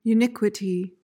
PRONUNCIATION:
(yoo-NIK-wuh-tee)